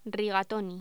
Locución: Rigatoni
voz
Sonidos: Voz humana